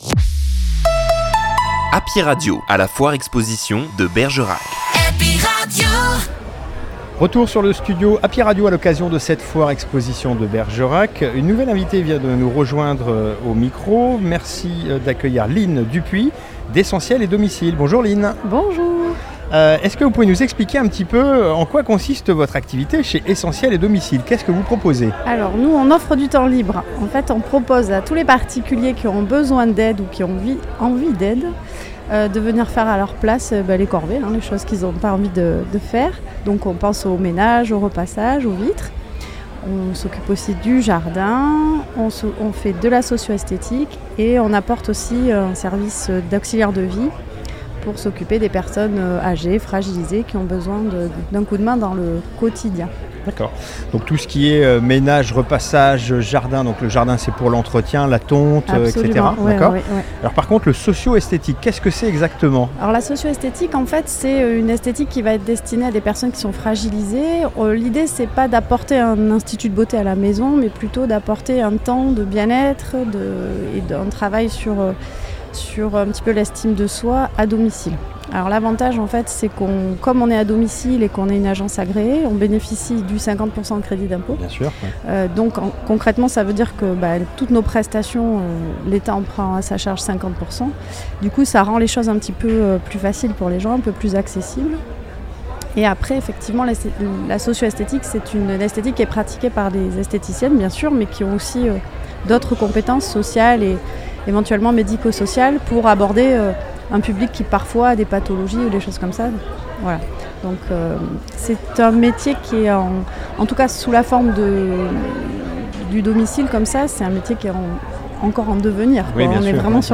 Foire Expo De Bergerac 2024